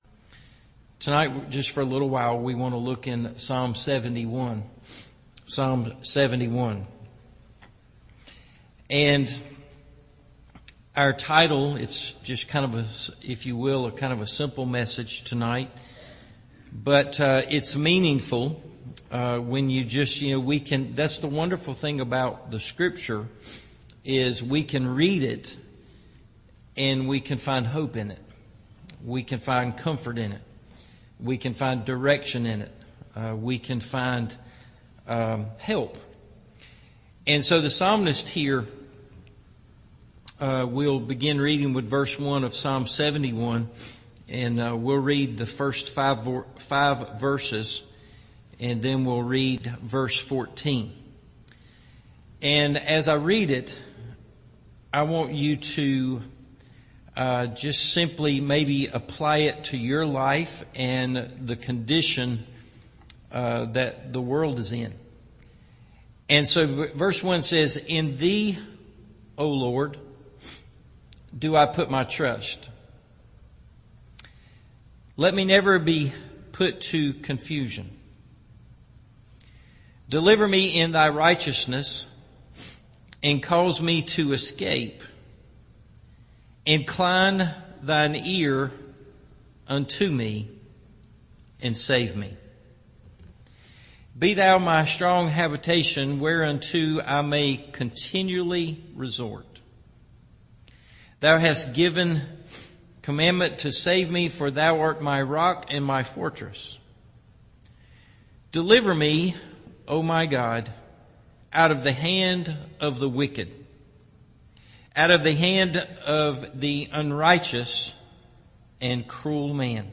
My Hope Is In The Lord – Evening Service